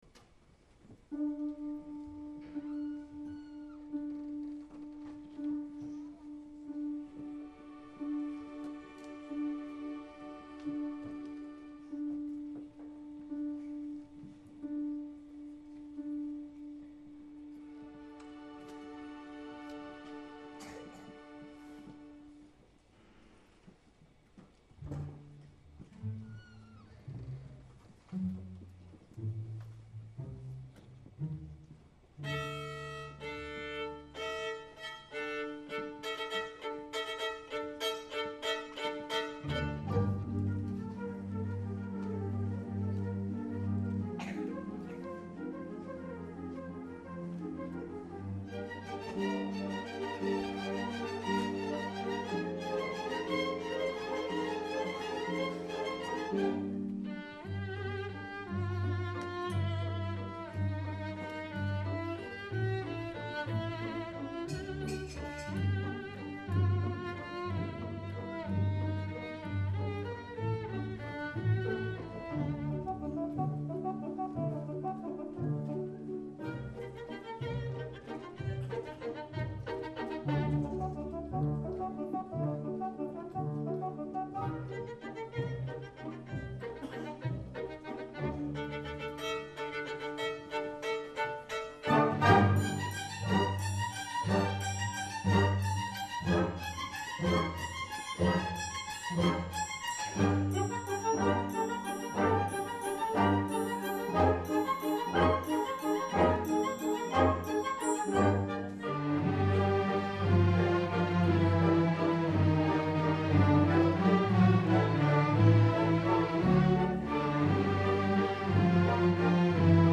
Album: Concert pédagogique 2011